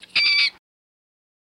Mink Scream, Chirp